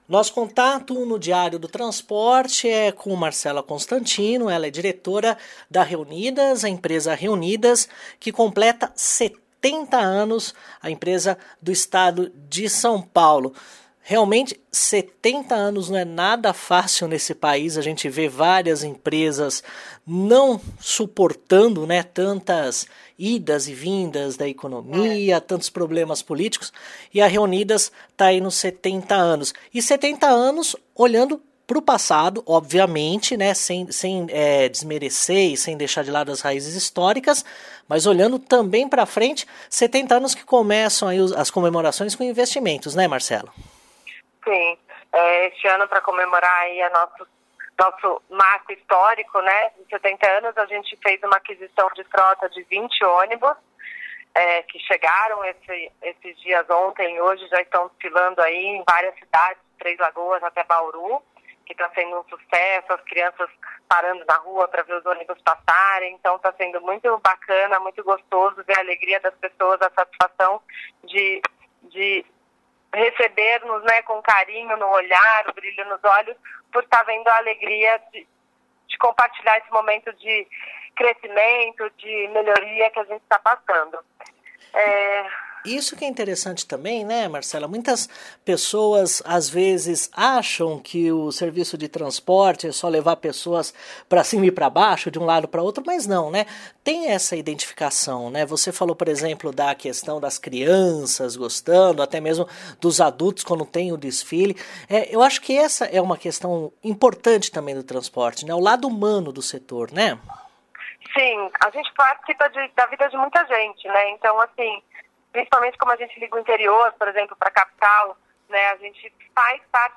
ENTREVISTA: Reunidas comemora 70 anos com 20 ônibus novos e com serviço leito para São Bernardo do Campo, Santos e Campinas